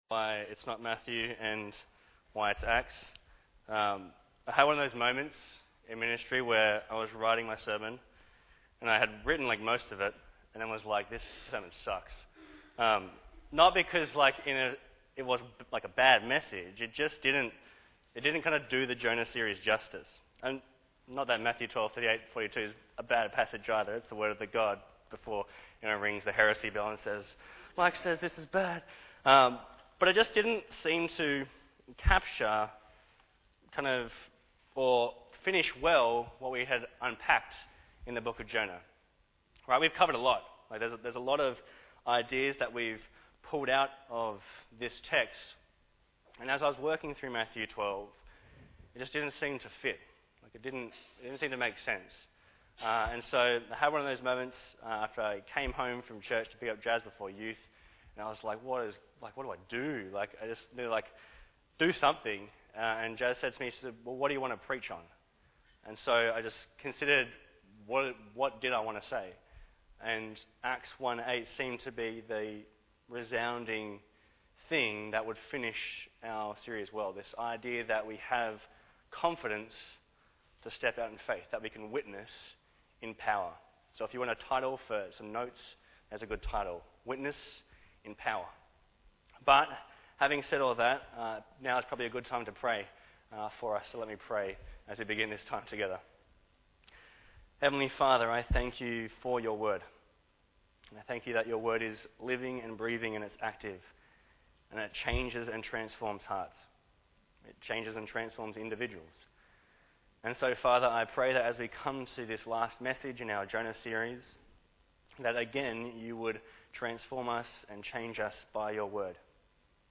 Bible Text: Matthew 12:38-42 | Preacher